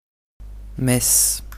Football Club de Metz (French pronunciation: [mɛs]
Prononciationmetz.ogg.mp3